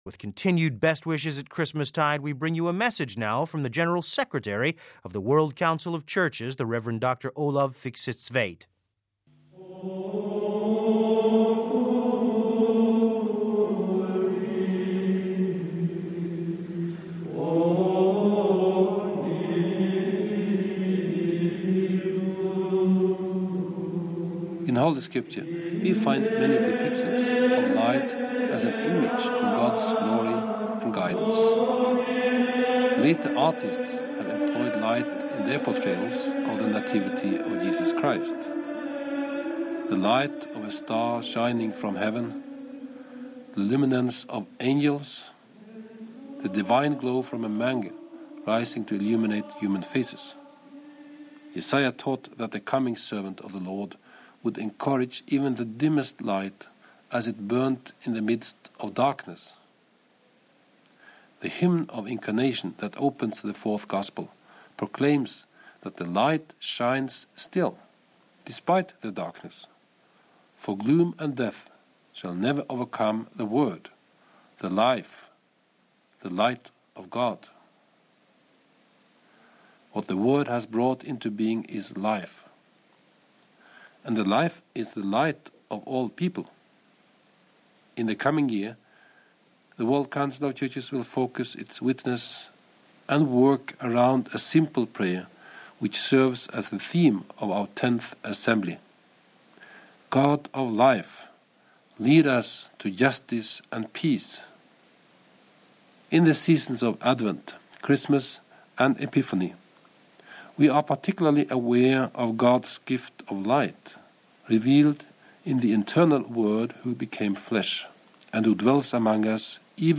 Christmas Message from Bishop Treanor
Christmas Message from Bishop Noel Treanor of the Diocese of Down and Connor